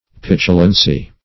Petulance \Pet"u*lance\, Petulancy \Pet"u*lan*cy\, n. [L.